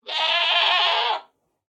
1.21.5 / assets / minecraft / sounds / mob / goat / scream1.ogg
scream1.ogg